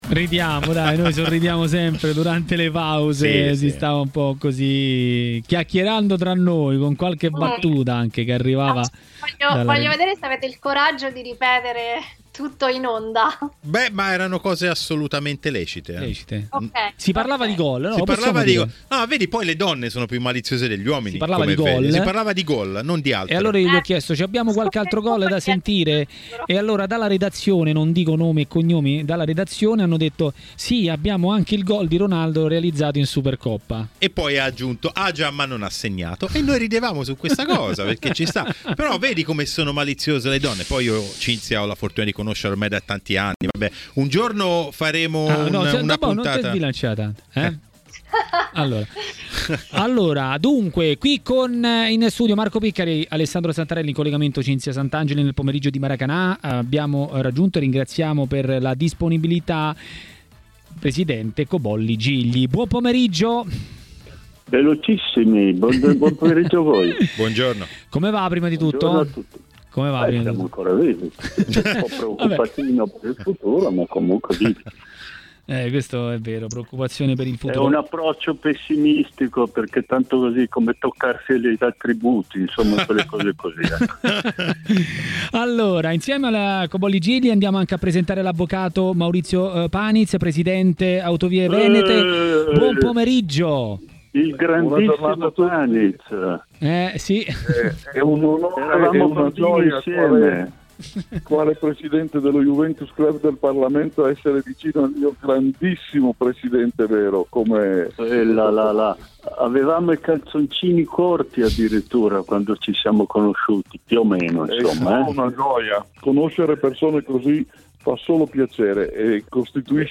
Maurizio Paniz, nuovo presidente di Autovie Venete, ha commentato così a TMW Radio, durante Maracanà, le ultime notizie sul mondo del calcio.